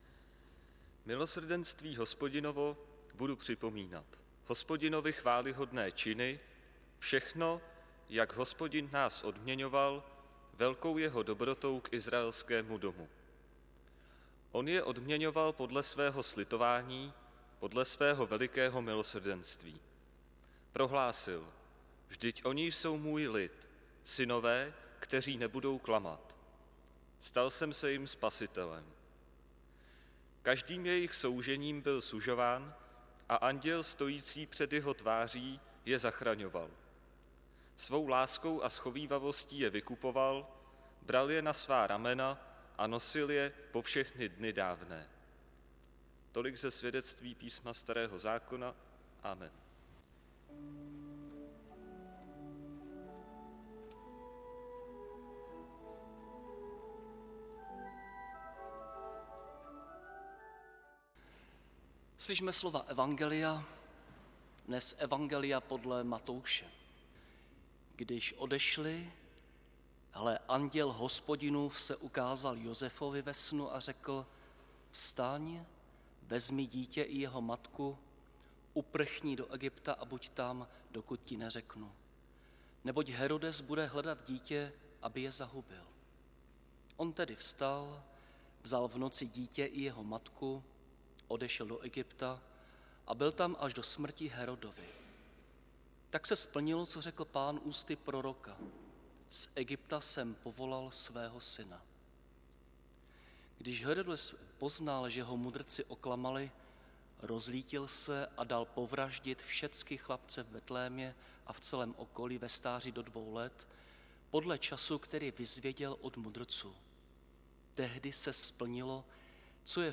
záznam kázání Matoušovo evangelium 2, 13 – 23